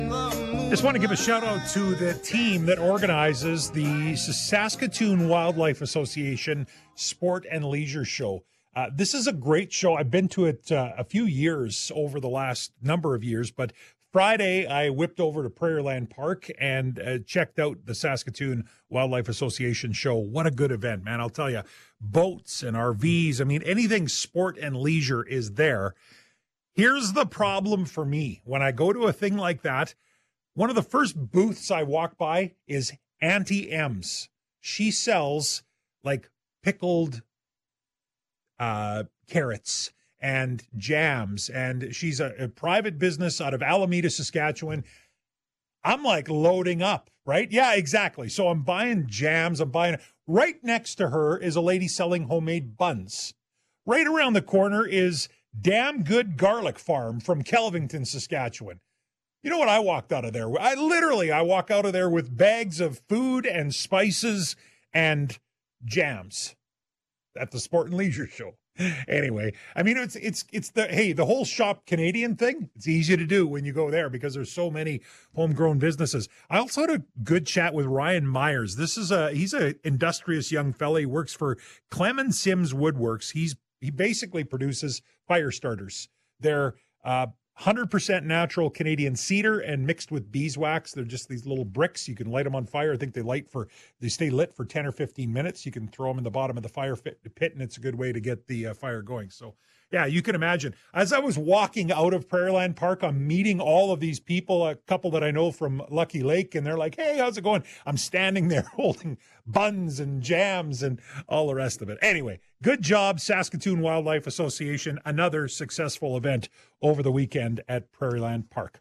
Hear us on the Radio!
Interview: 72nd Sports & Leisure Show